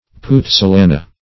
Puzzolana \Puz`zo*la"na\